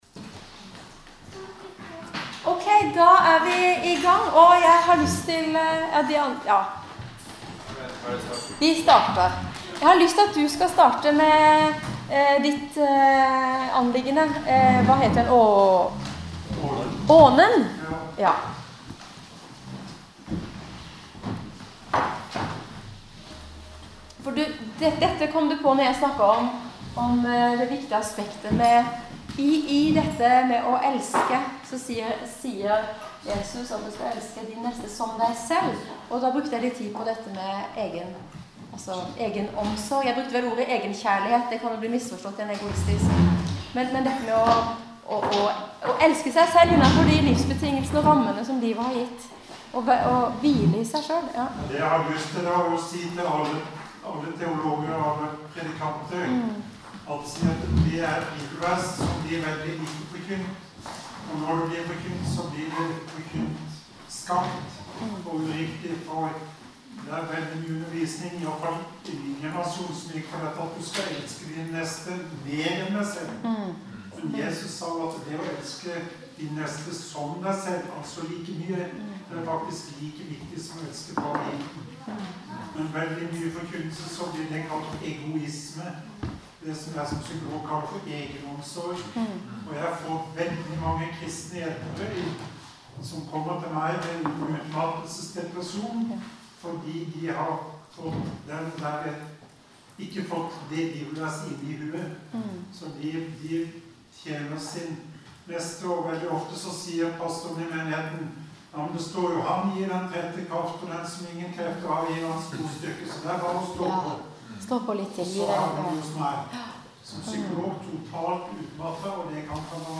Lydopptak fra kjærlighetskonferansen